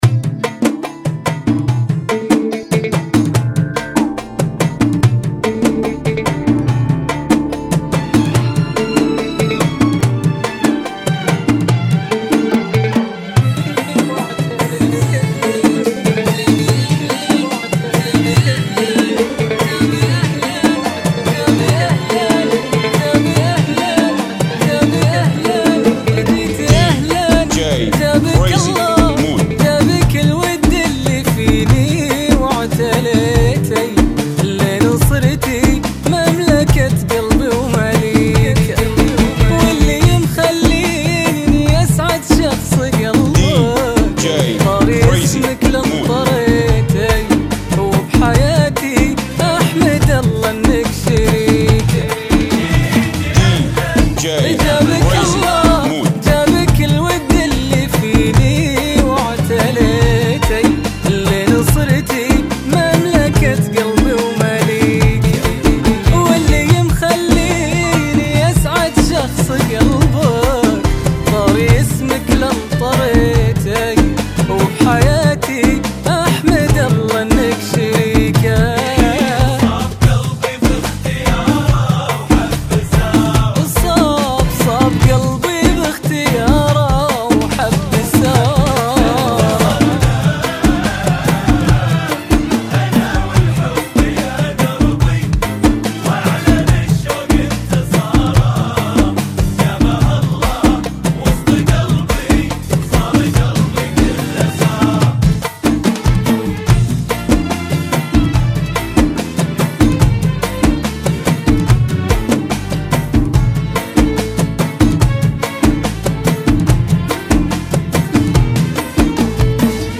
Funky [ 72 Bpm ]